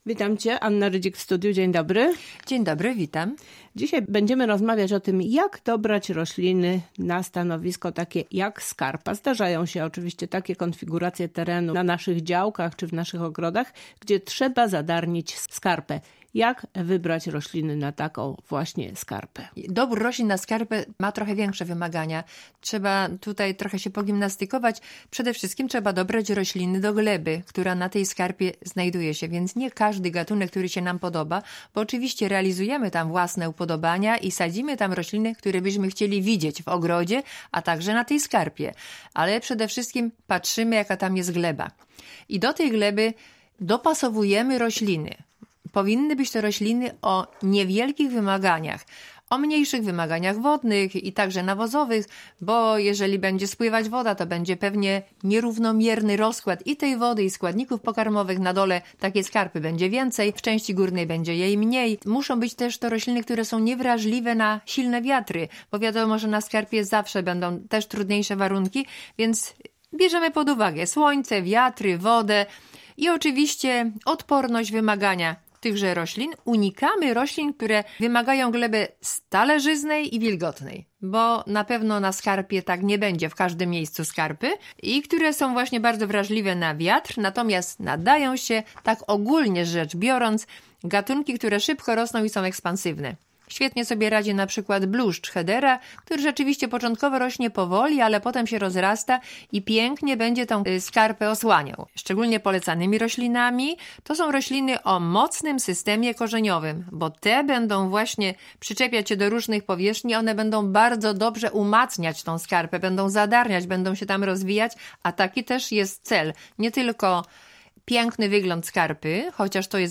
O nich właśnie rozmawiają dziś w audycji